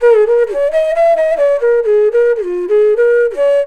FLUTE-B02 -R.wav